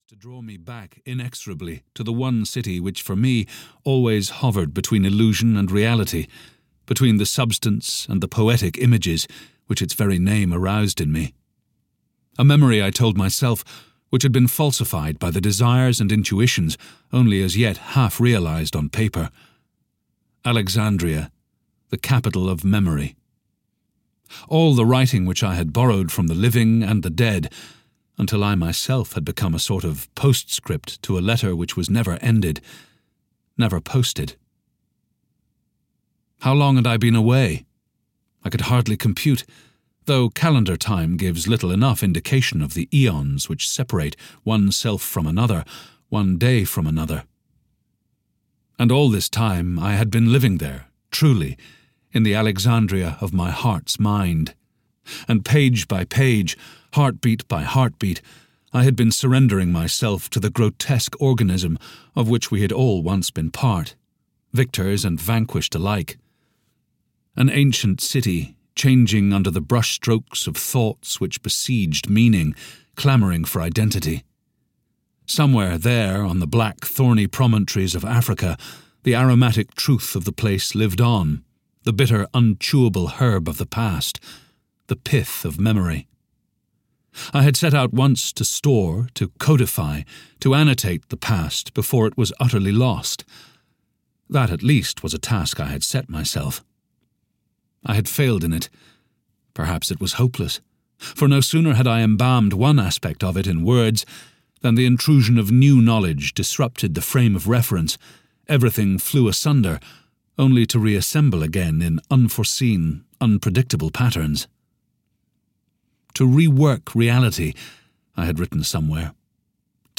Clea (EN) audiokniha
Ukázka z knihy